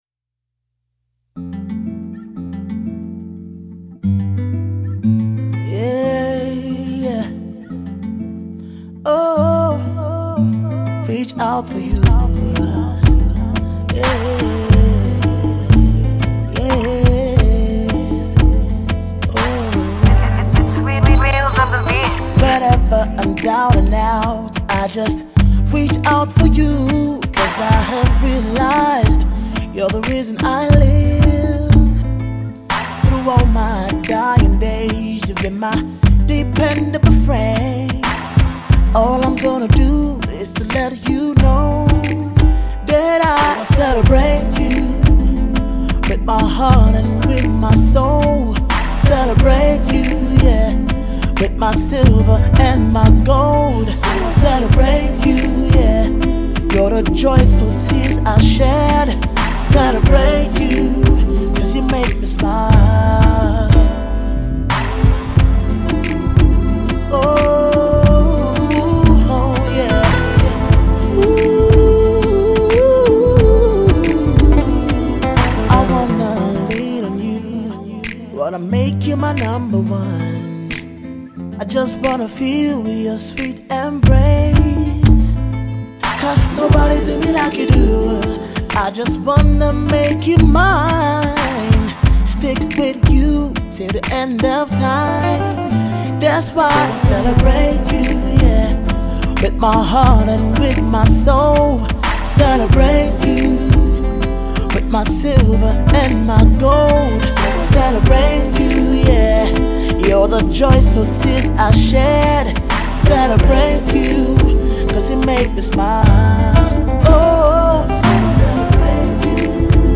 super cool jam
smooth jam